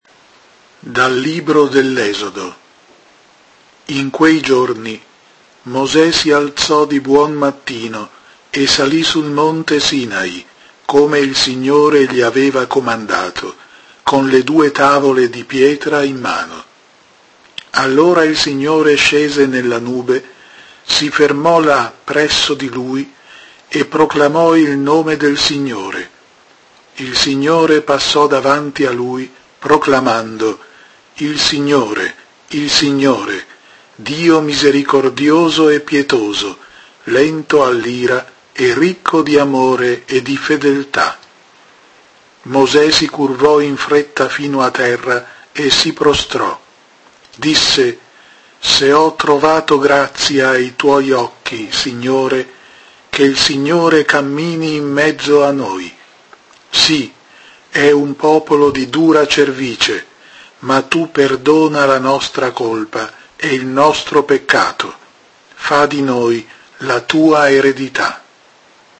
Prima lettura –